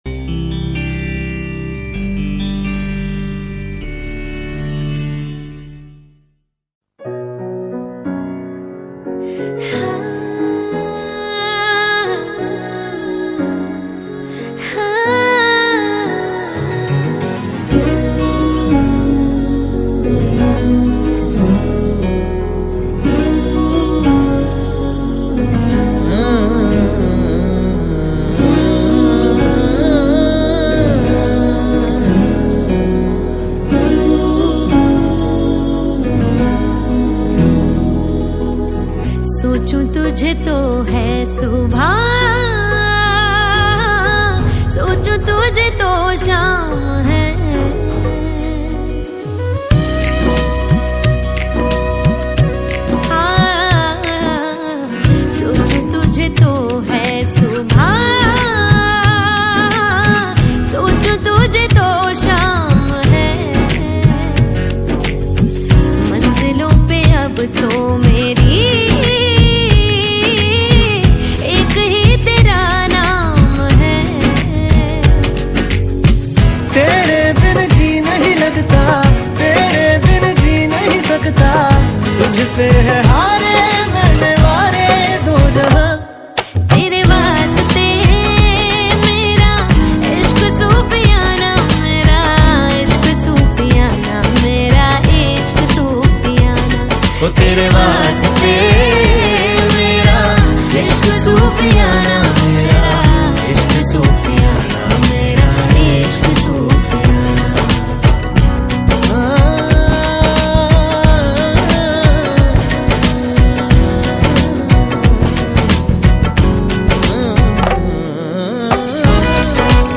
Hindi Mixtape Songs